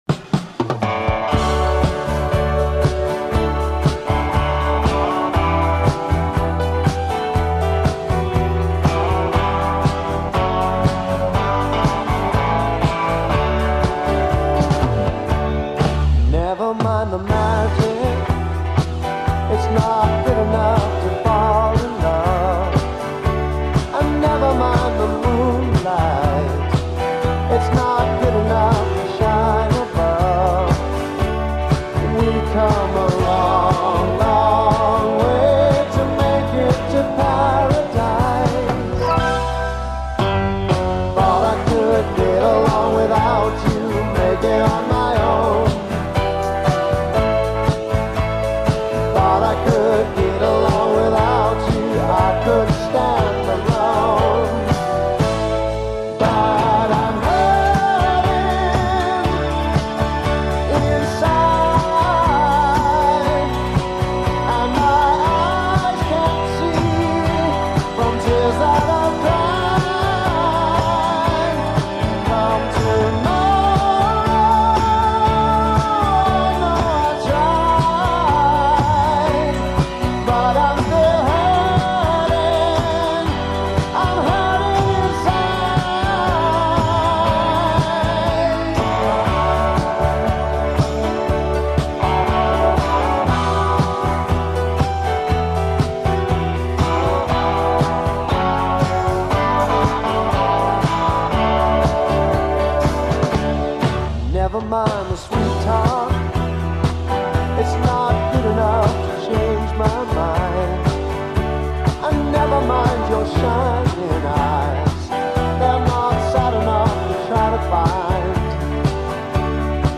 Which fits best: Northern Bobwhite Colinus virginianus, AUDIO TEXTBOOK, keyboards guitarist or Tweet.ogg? keyboards guitarist